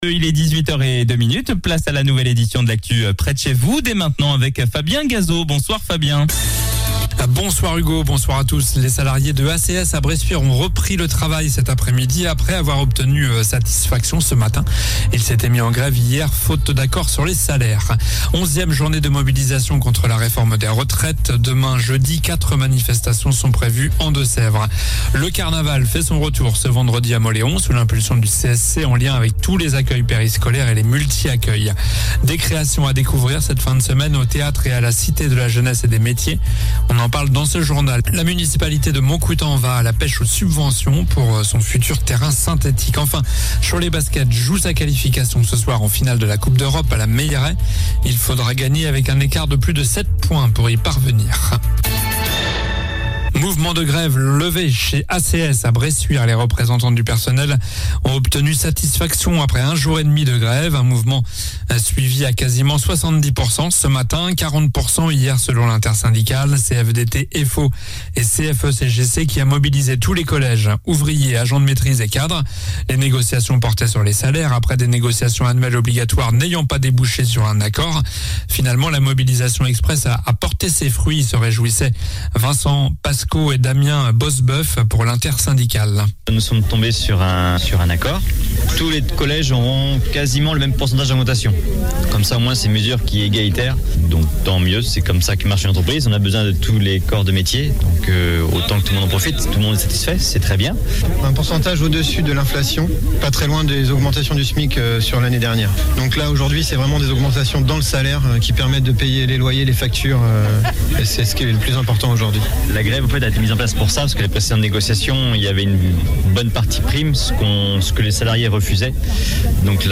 Journal du mercredi 06 avril (soir)